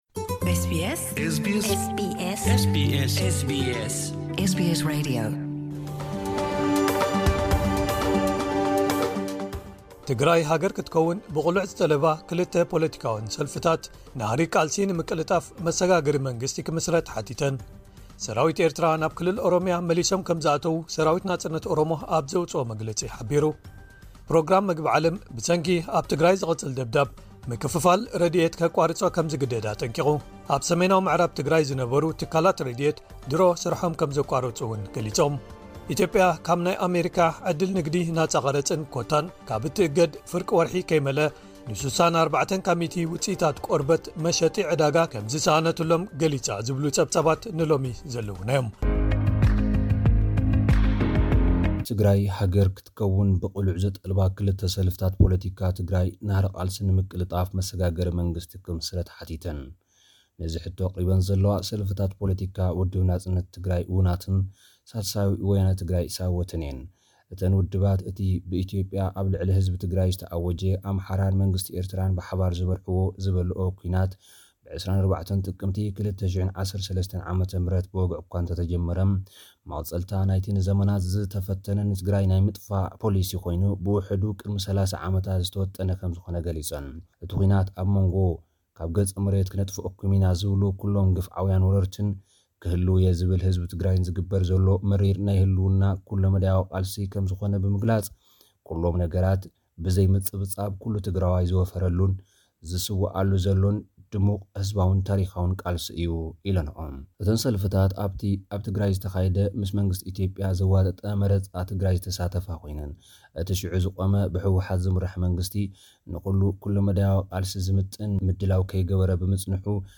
ሓጸርቲ ጸብጻባት ዜና